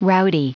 Prononciation du mot rowdy en anglais (fichier audio)
Prononciation du mot : rowdy
rowdy.wav